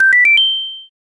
The Pin-pon-pan-pon sound effect from Super Mario RPG: Legend of the Seven Stars
Signal_Ring.mp3